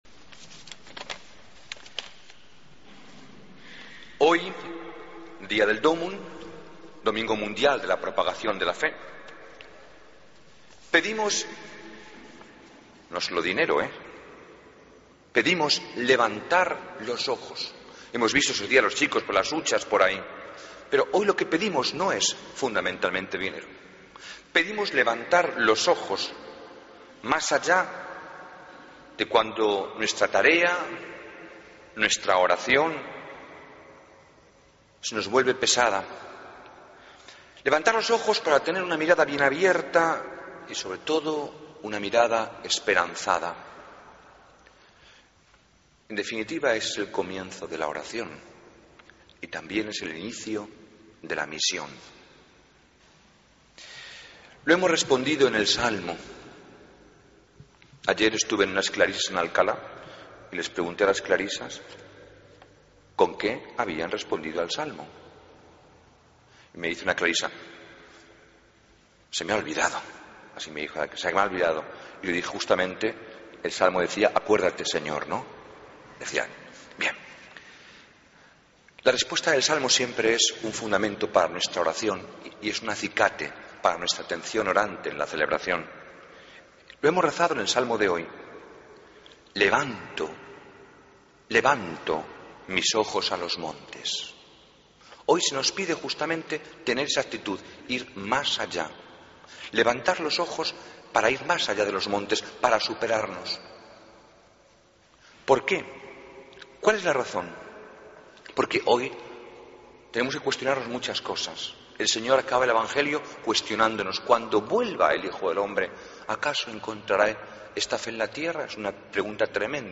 Homilía Domingo 20 Octubre 2013